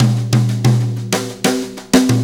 Brushes Fill 69-11.wav